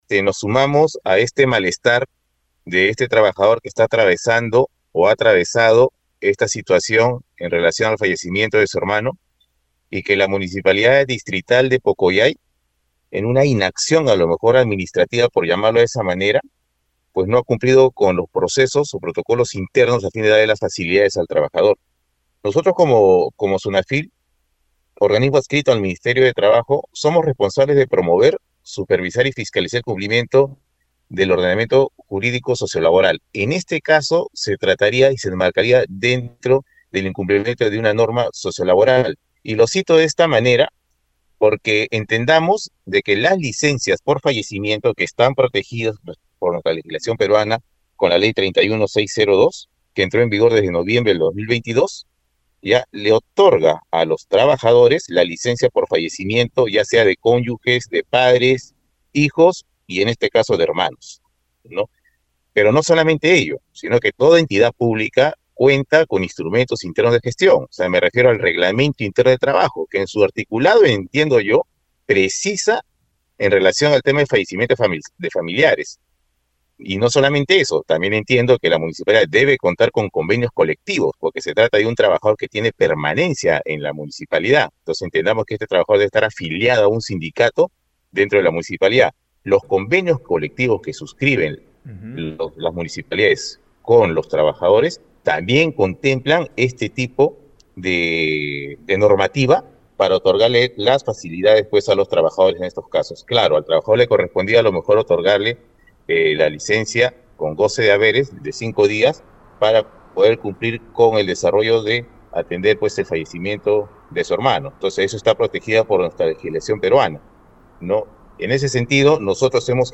eduardo-perez-intendente-regional-sunafil-tacna.mp3